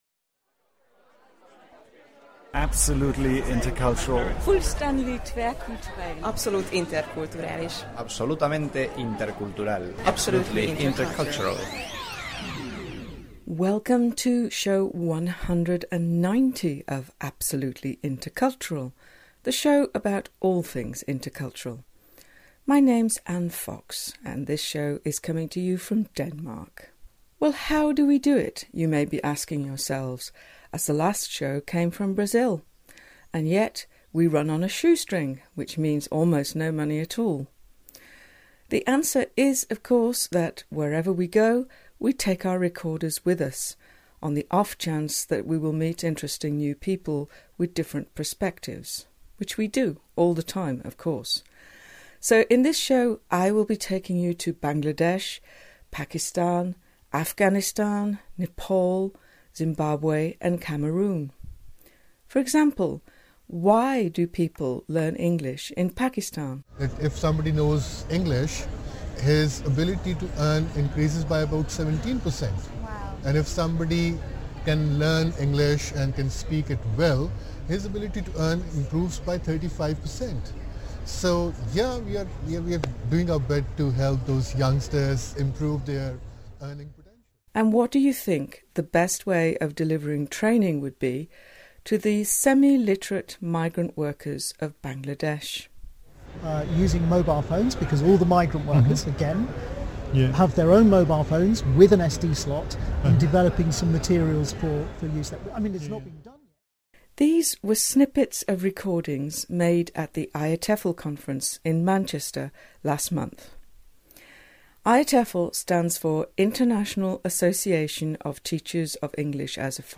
In this show we’re going to be hearing about why knowledge of the local culture is important, how different cultures understand the notion of a contract, what sort of intercultural problems business people have, the right and wrong way to express disagreement and lots more. In the past we have brought you tasters from various conferences on intercultural topics and in this show we are very lucky to have been given permission to bring you extracts from the recent online webinar organised by IATEFL’s Business English special interest group on the topic of intercultural communication.